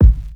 KICK_WHISTLE.wav